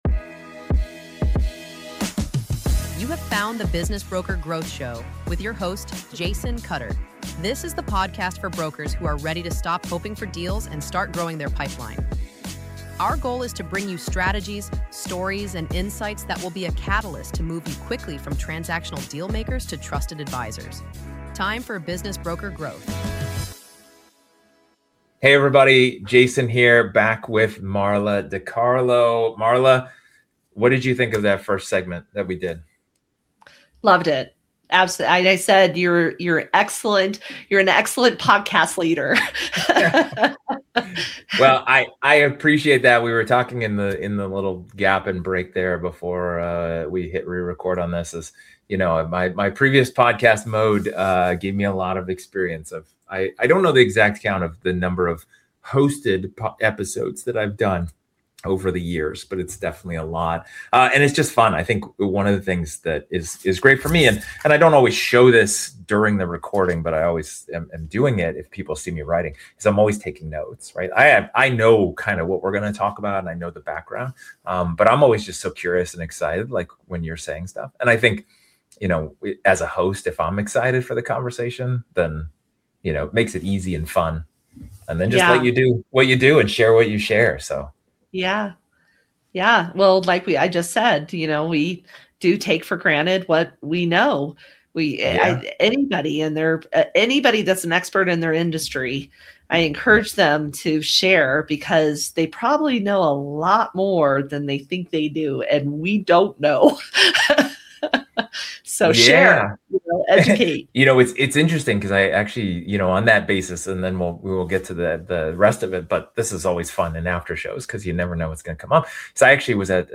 unfiltered after-show conversation